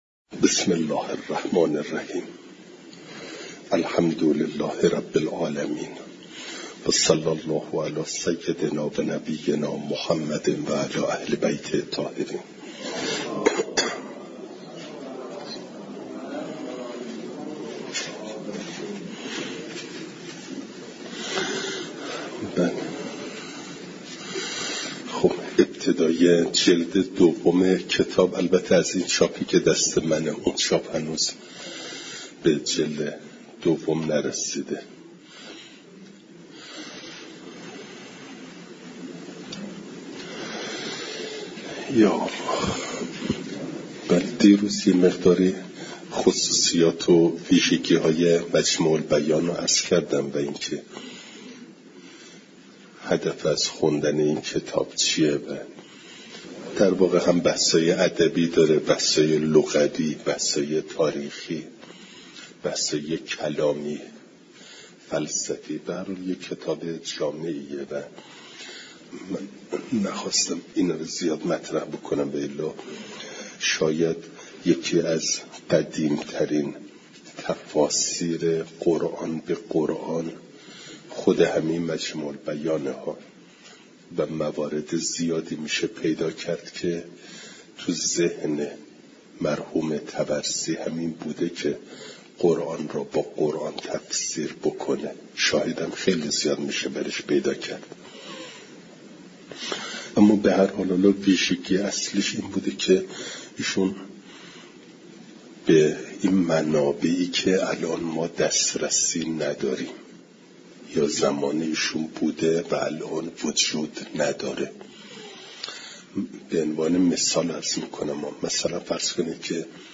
فایل صوتی جلسه صد و هفتاد و یکم درس تفسیر مجمع البیان